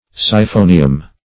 Search Result for " siphonium" : The Collaborative International Dictionary of English v.0.48: Siphonium \Si*pho"ni*um\, n.; pl.